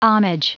Prononciation du mot homage en anglais (fichier audio)
Prononciation du mot : homage